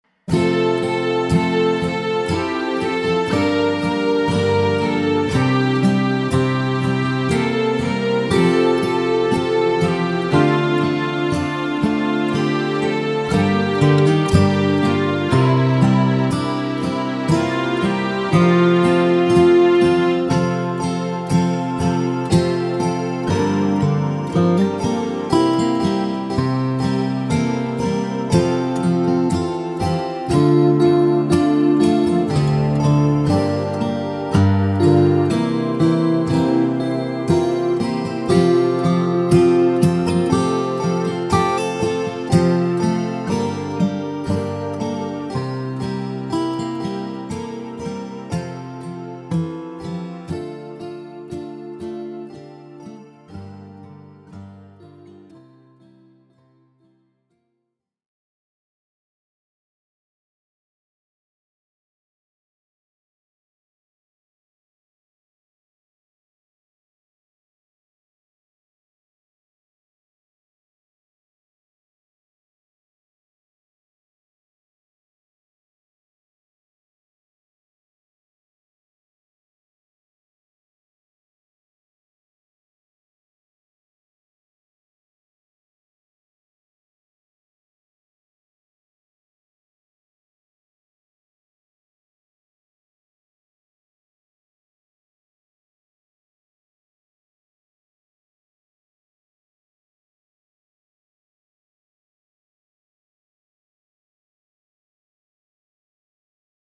Latviešu tautas dziesma ar fonogrammu